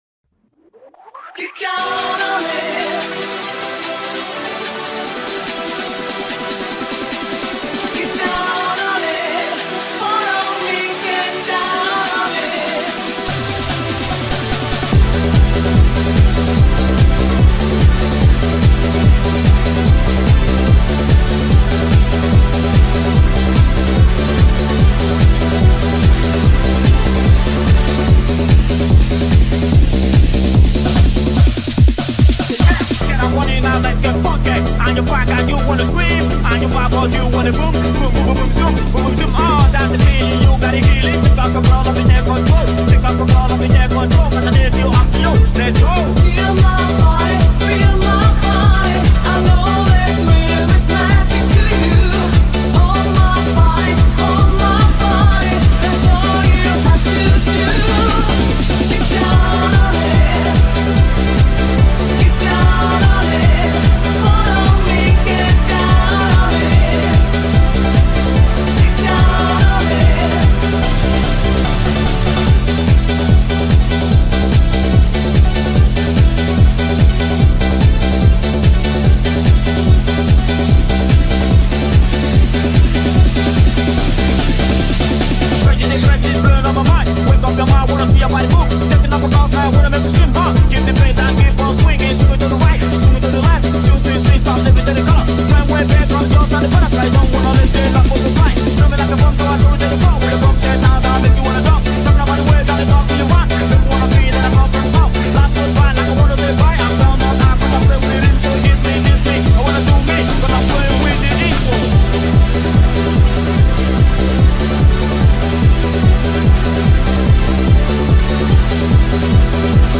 EuroDance Mix
EuroDance-Mix.mp3